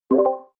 discord-unmute.mp3